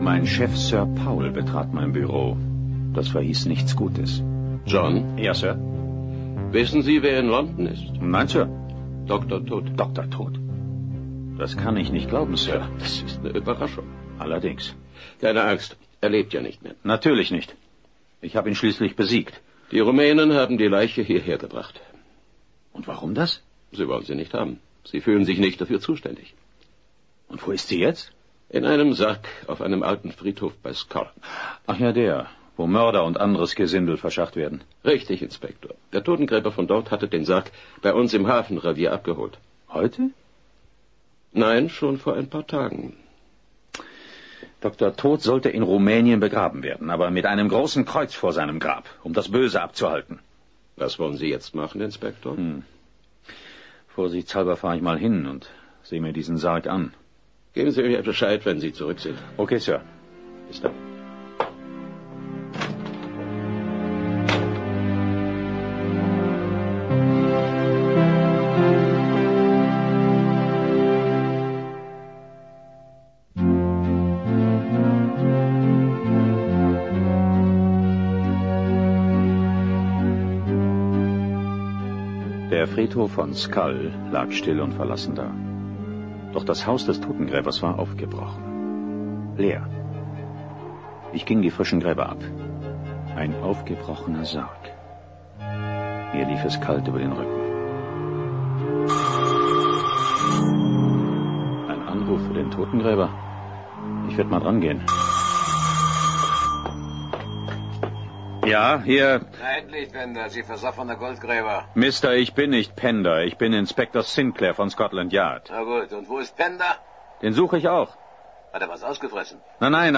Insgesamt handelt es sich um ein sehr gelungenes Hörspiel.